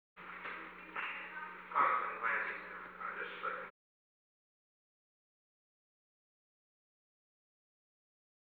Conversation: 921-017
Recording Device: Oval Office
The Oval Office taping system captured this recording, which is known as Conversation 921-017 of the White House Tapes.
The President met with an unknown man and an unknown woman.